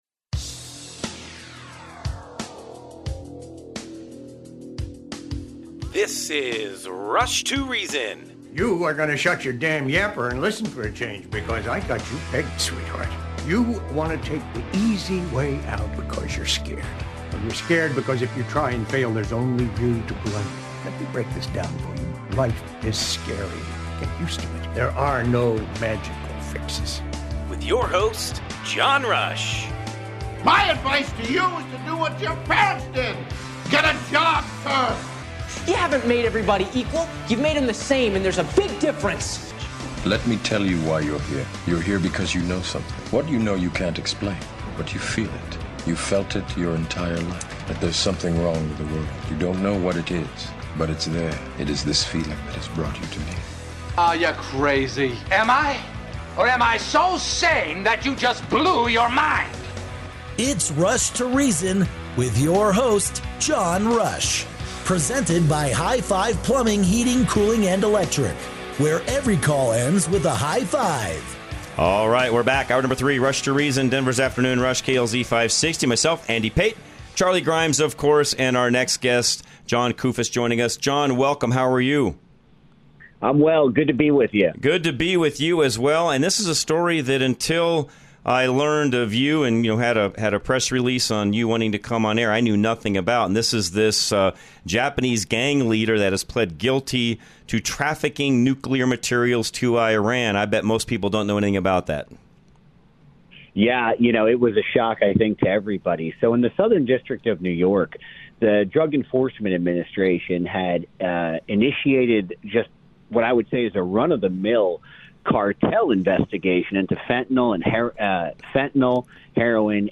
Rush To Reason - Interviews